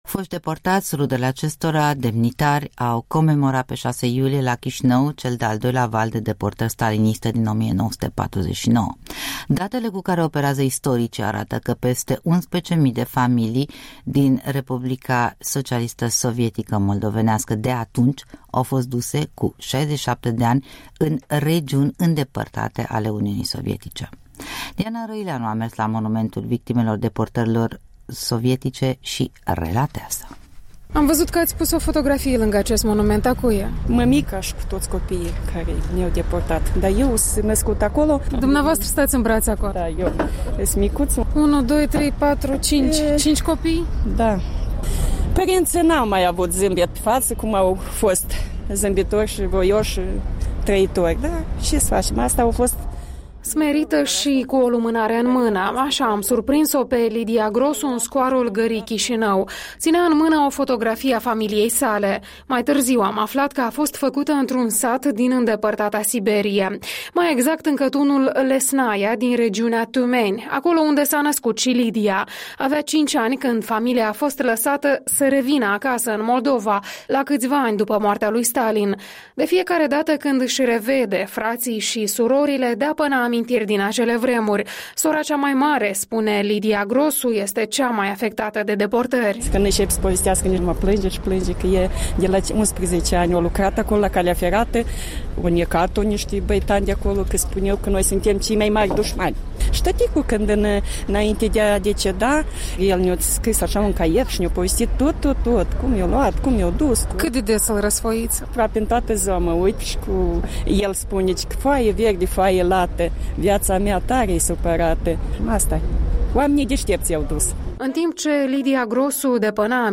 Mărturii ale deportaților despre îndepărtata Siberie...
Un reportaj realizat la Monumentul victimelor deportărilor regimului comunist.